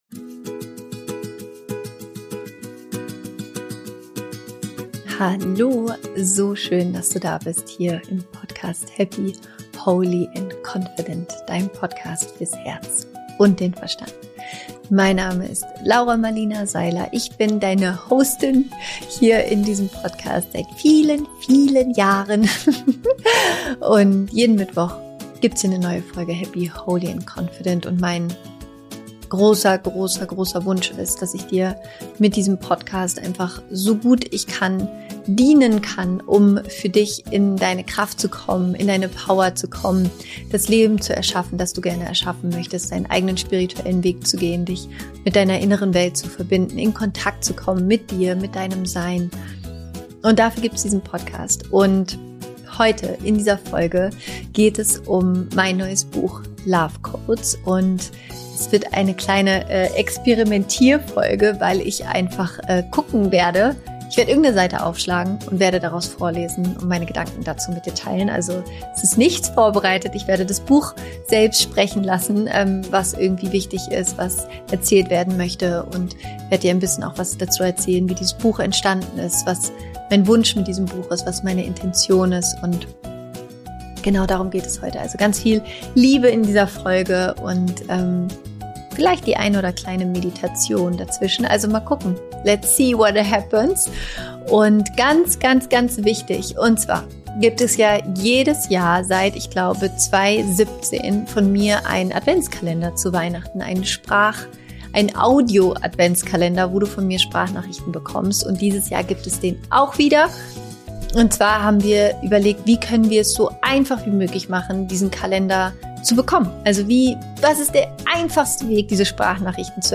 In meiner neuen Podcastfolge lese ich dir nun aus genau diesem neuen Buch LOVE CODES vor. Außerdem wartet eine kurze Meditation auf dich, in der du deinen eigenen LOVE CODE empfangen wirst.